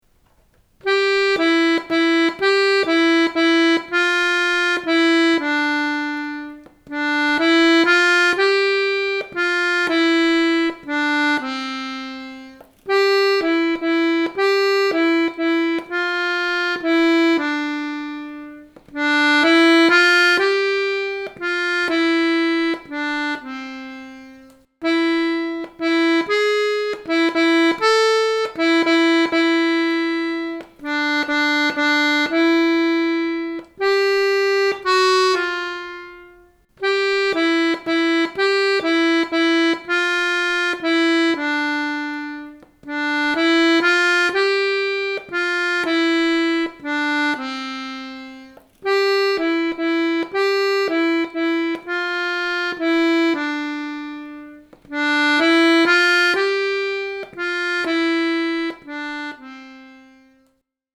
Melodía:
Esta é unha canción de berce: emprégase para durmir os bebés, con sons doces e tranquilos.